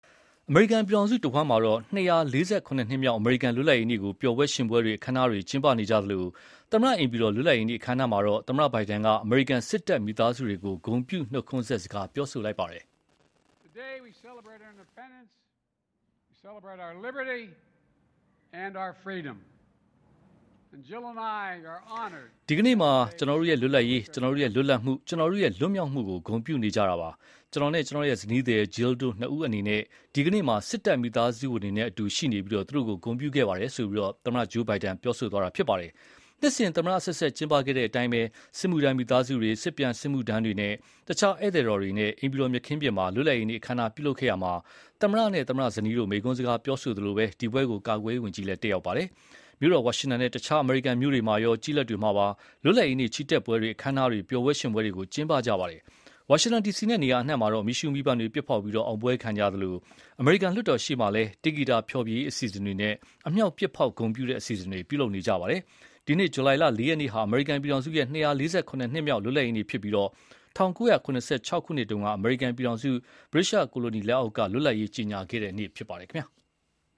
အမေရိကန်ပြည်ထောင်စု တဝှမ်းမှာ ၂၄၇နှစ်မြောက် အမေရိကန်လွတ်လပ်ရေးနေ့ကို ပျော်ပွဲရွှင်ပွဲတွေ အခမ်းအနားတွေ ကျင်းပနေကြသလို၊ သမ္မတအိမ်ဖြူတော် လွတ်လပ်ရေးနေ့အခမ်းအနားမှာလည်း သမ္မတဘိုင်ဒန်က အမေရိကန်စစ်တပ်မိသားစုတွေကို ဂုဏ်ပြုနှုတ်ခွန်းဆက်စကား ပြောဆိုပါတယ်။